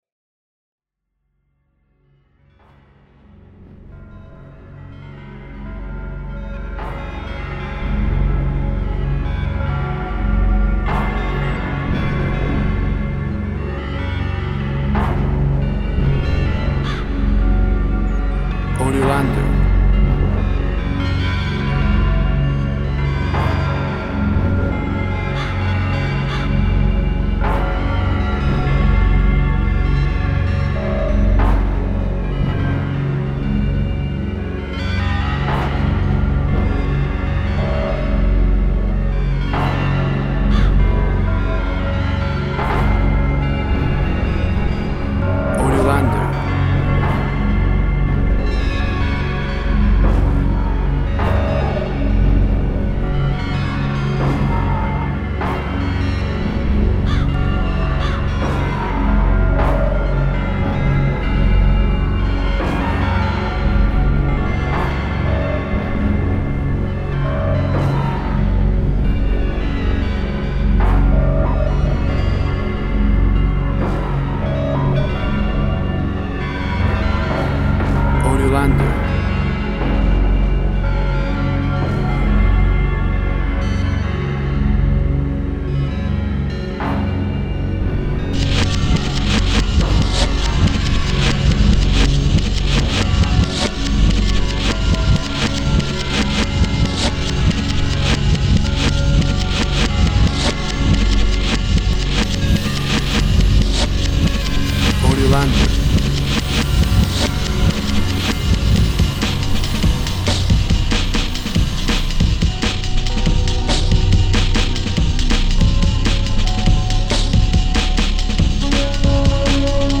WAV Sample Rate 16-Bit Stereo, 44.1 kHz
Tempo (BPM) 95